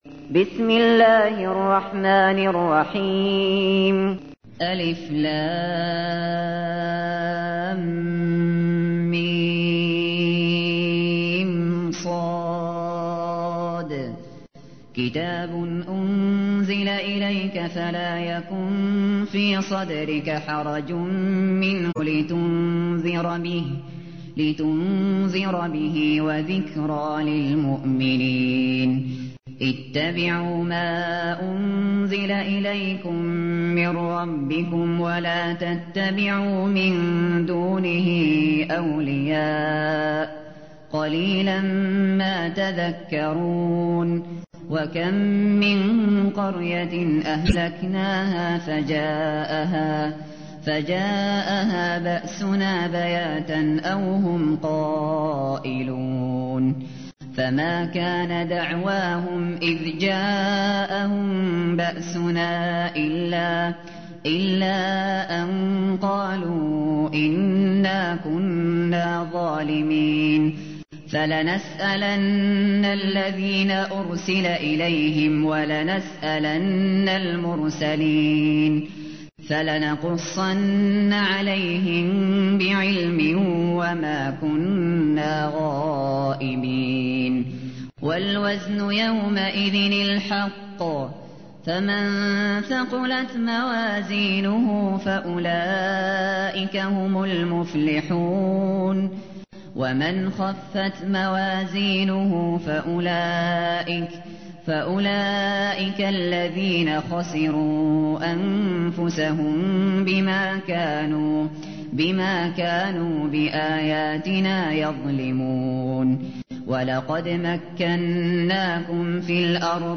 تحميل : 7. سورة الأعراف / القارئ الشاطري / القرآن الكريم / موقع يا حسين